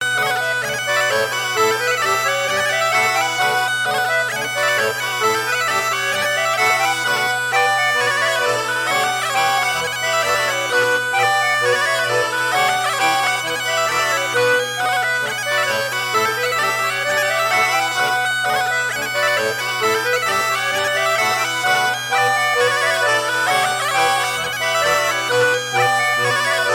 danse : branle : courante, maraîchine
Pièce musicale éditée